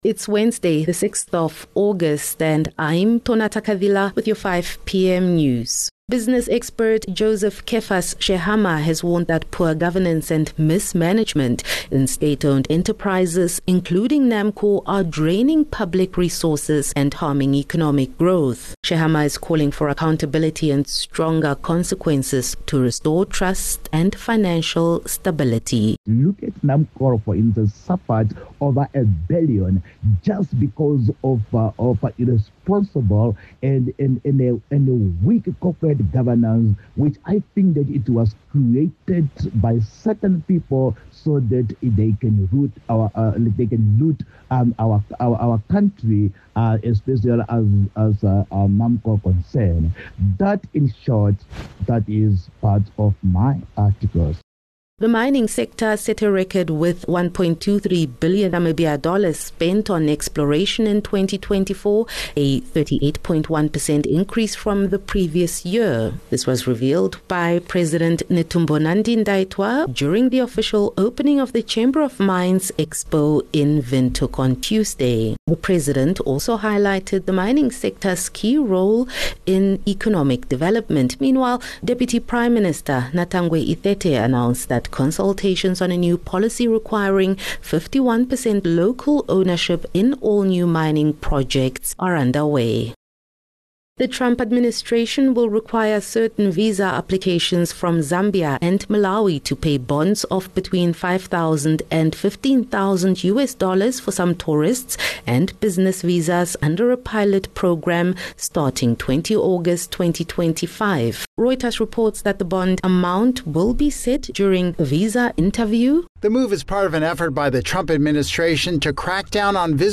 6 Aug 6 August - 5 pm news